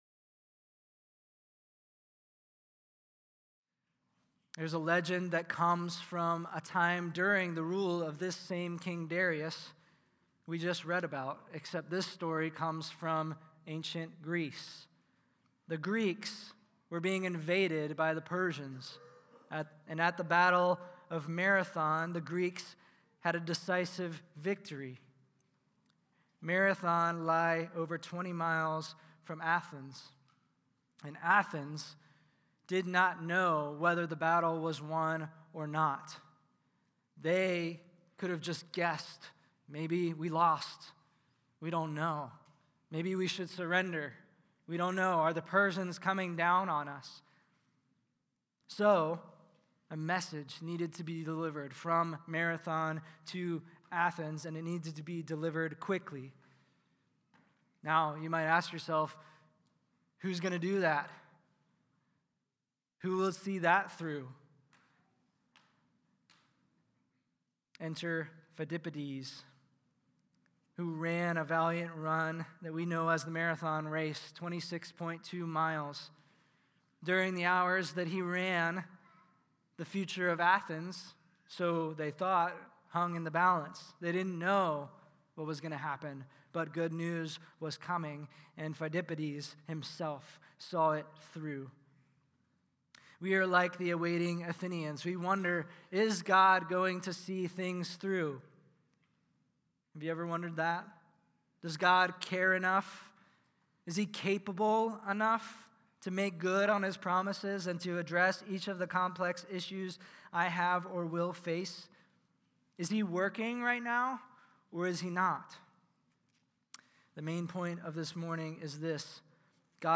Messages given at the Sunday Morning Celebration Gathering of Sovereign Grace Church Dayton